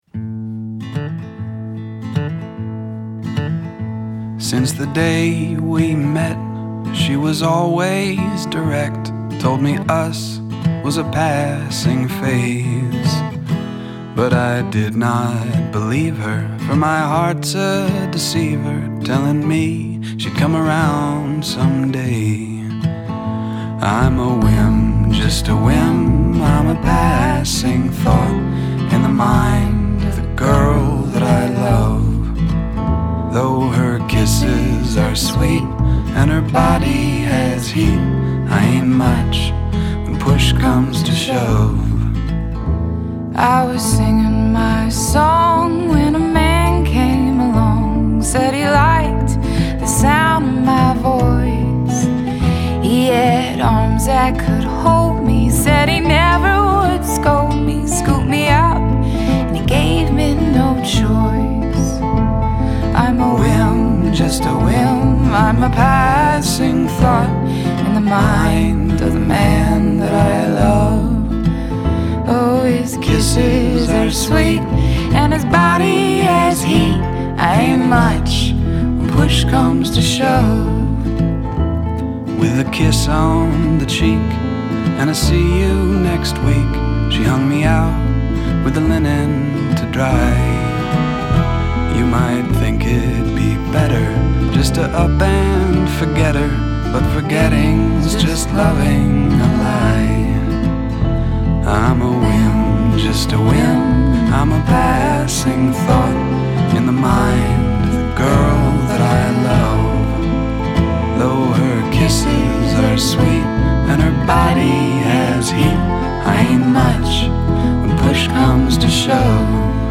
An easy-going singer/songwriter duet
sings with unaffected richness